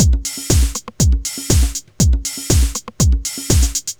Index of /musicradar/retro-house-samples/Drum Loops
Beat 04 Full (120BPM).wav